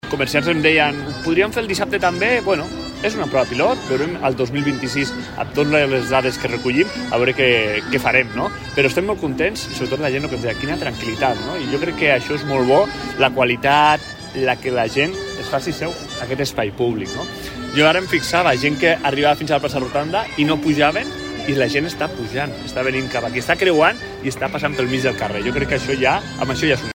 El cònsol major d’Andorra la Vella, Sergi González, ha valorat positivament aquesta primera jornada.